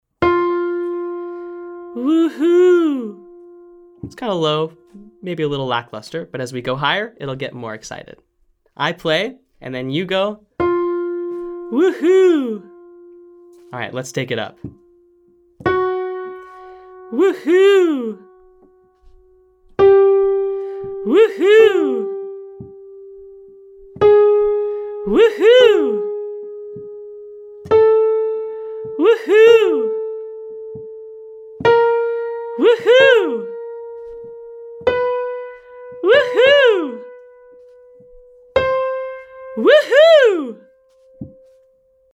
The exercise is just approximating, or getting close to, the pitch I play on the piano.